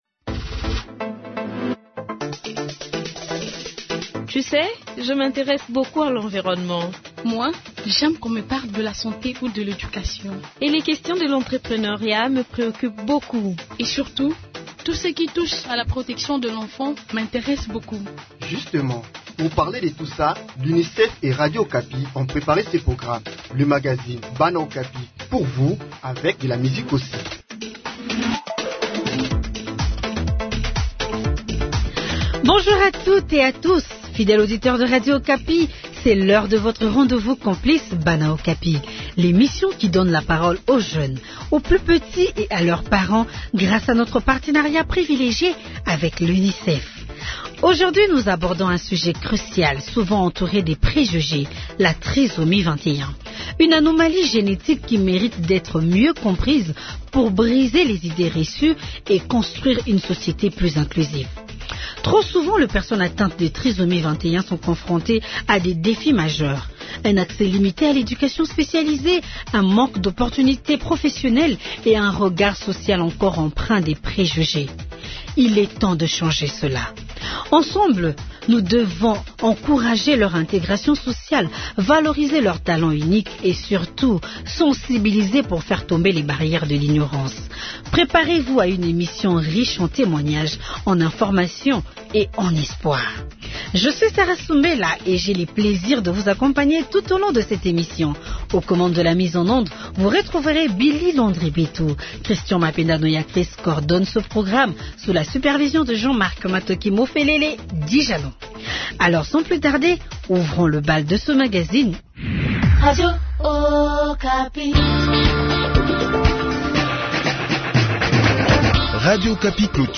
Préparez-vous à une émission riche en témoignages, en informations et en espoir !